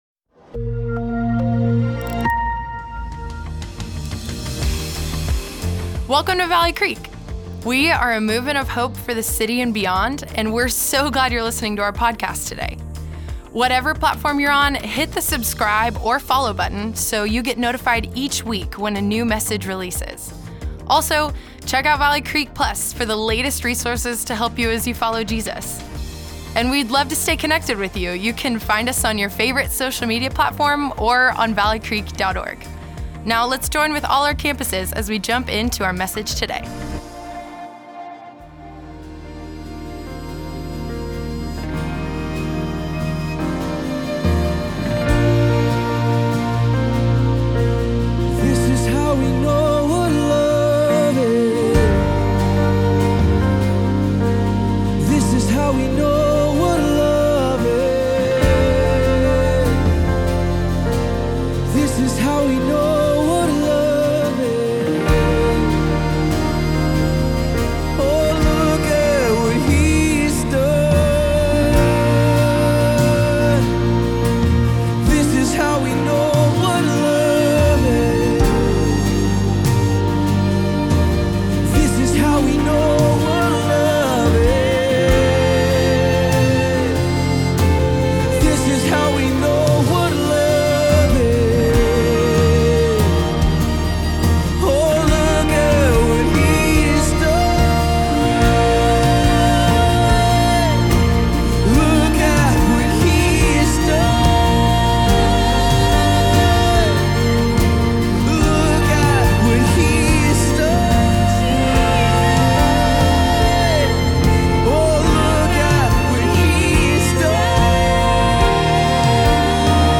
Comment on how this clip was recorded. During this service, we start 2025 together worshipping and focusing on Jesus.